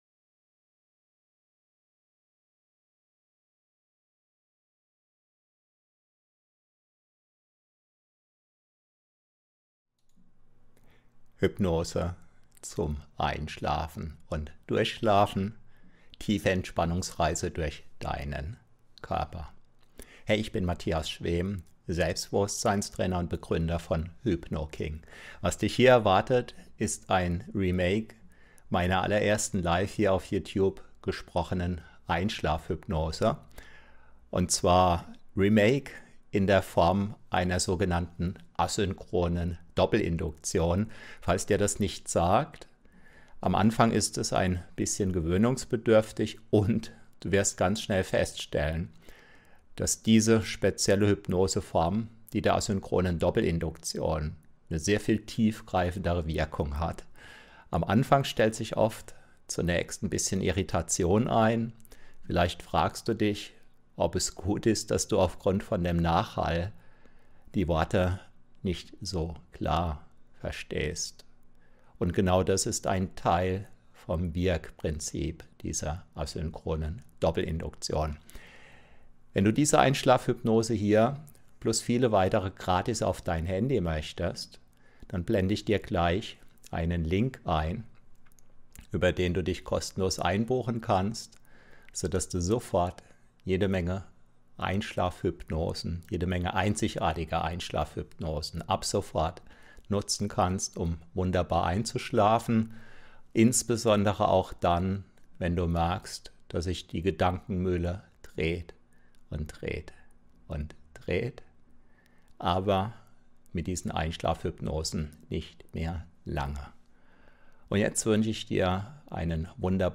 HypnoKing®: Hypnose zum Einschlafen und Durchschlafen - 😴Hypnose zum Einschlafen & Durchschlafen: Tiefe Entspannungsreise durch den Körper😴💤💤💤🌙